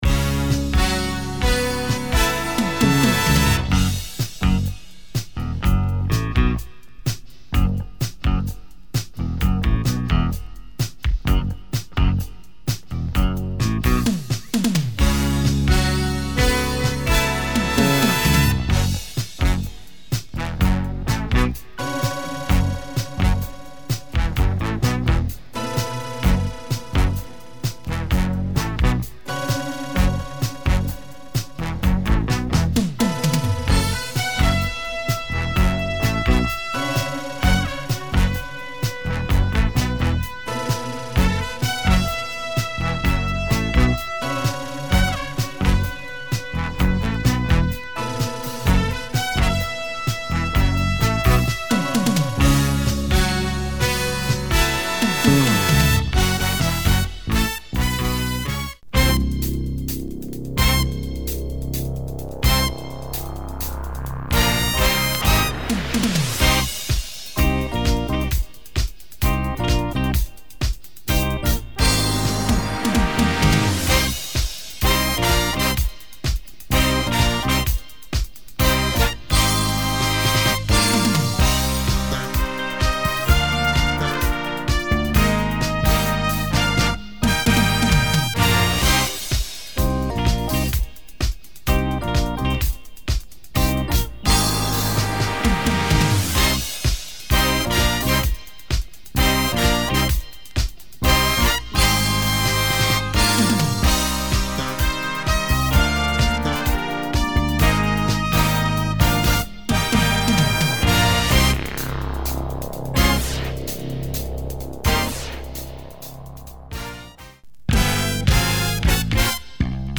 Seldom seen US library including the dope funky jazz
with slap bass